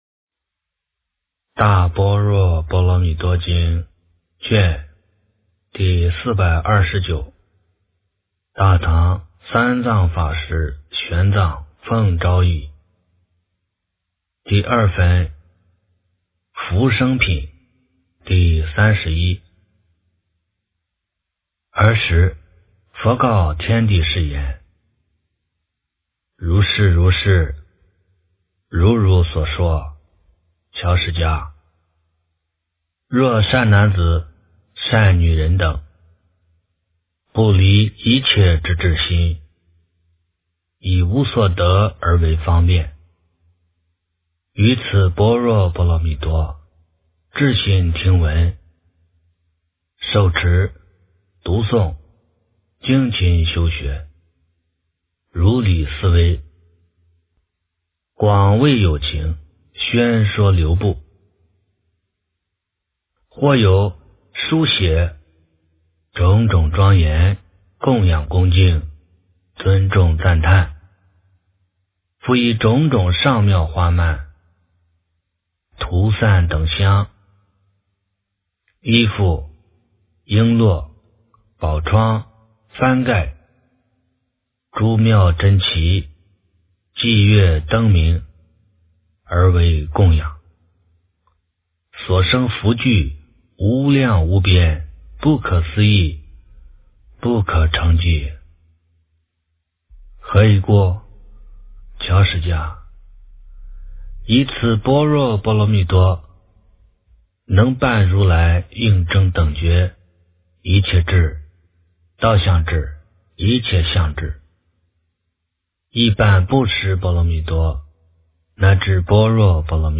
大般若波罗蜜多经第429卷 - 诵经 - 云佛论坛